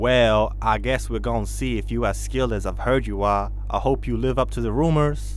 Voice Lines
Update Mayor Dialogue Tree for Voice Overs